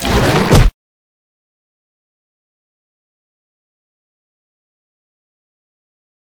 vpunch2.ogg